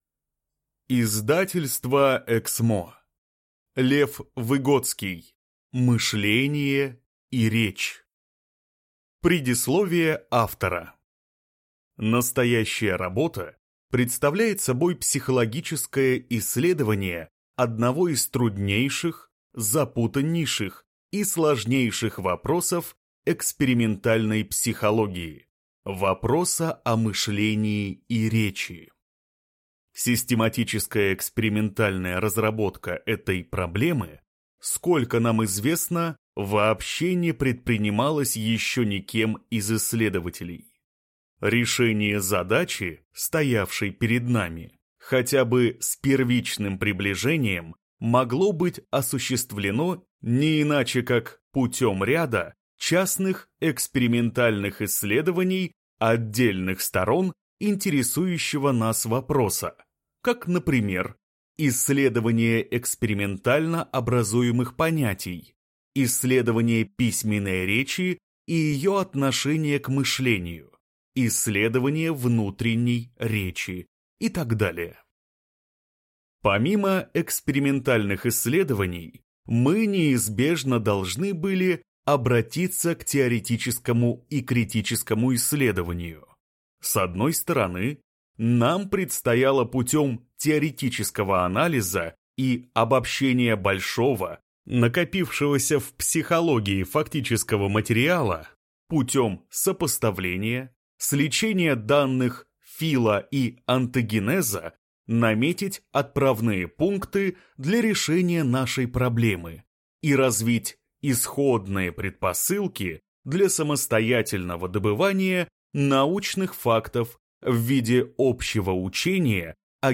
Аудиокнига Мышление и речь | Библиотека аудиокниг